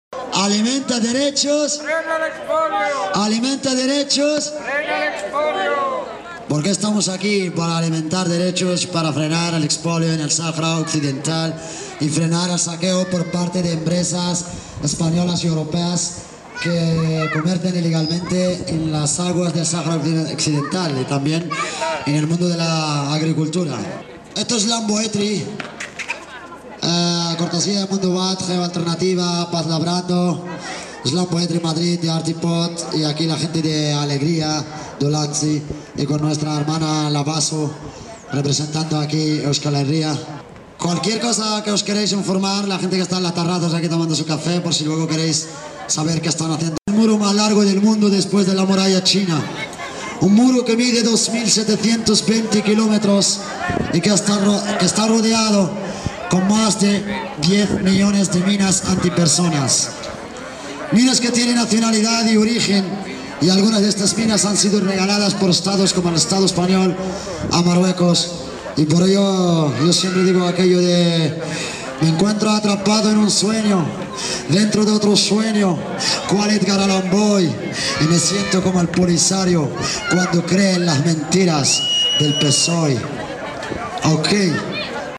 Escuchemos las diferentes intervenciones musicales sobre la descolonización del pensamiento y la palabra: